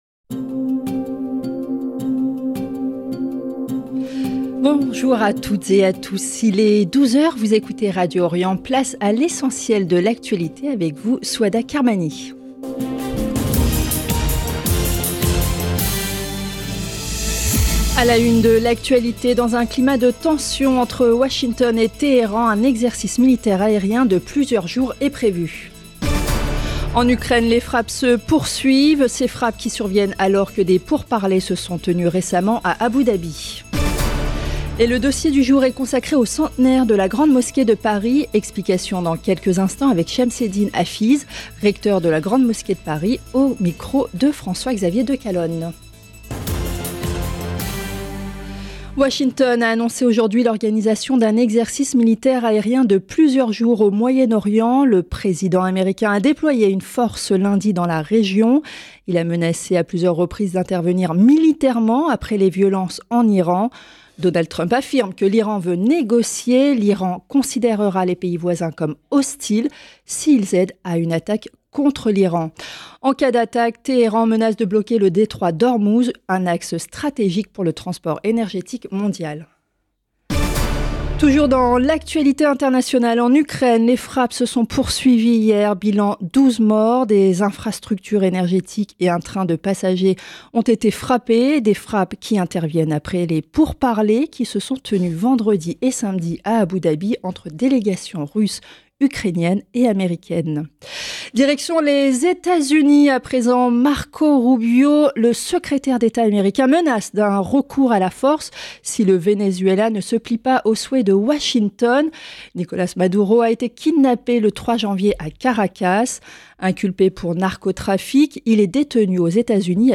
Radio Orient JOURNAL DE MIDI